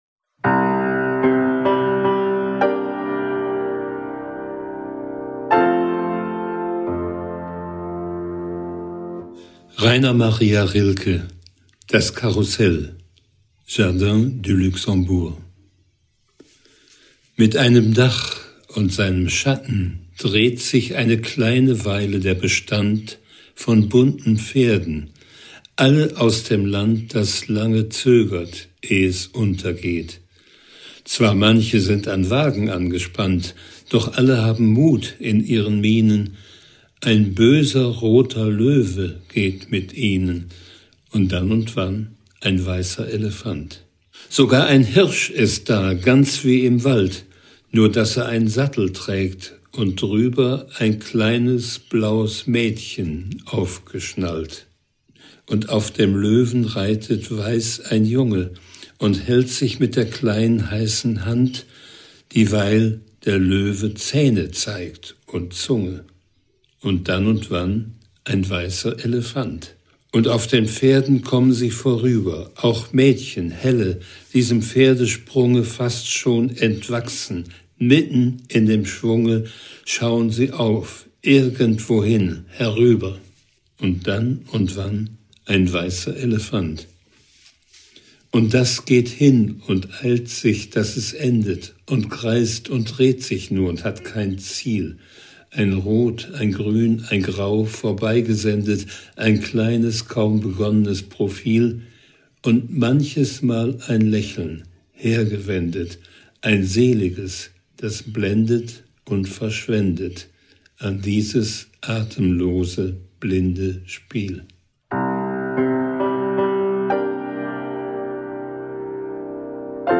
Gelesen von Harald Hartung, einem der profundesten Kenner abendländischer Lyrik, lädt dieser Podcast ein, mit Texten von Dichterinnen und Dichtern auf Reisen imaginär in die Ferne zu schweifen.
von und mit by ITB Berlin, gelesen von Harald Hartung